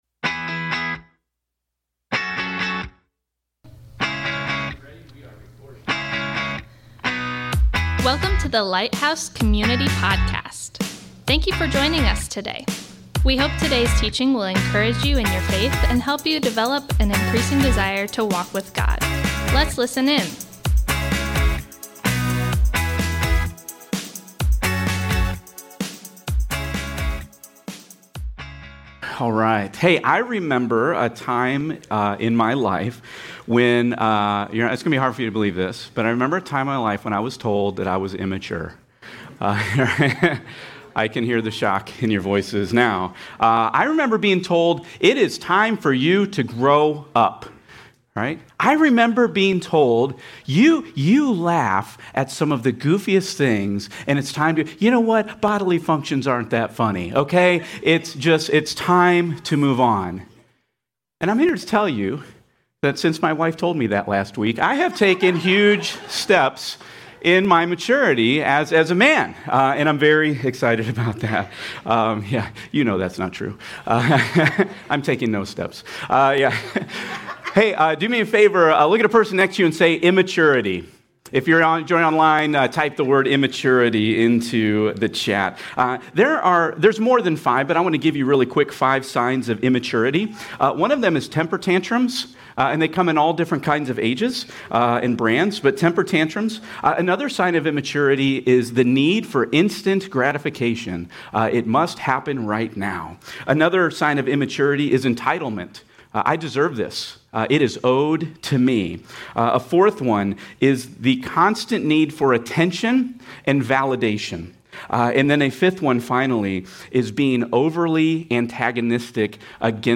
Thanks for joining us today as we worship together. We are starting a new teaching series today called "All In Together".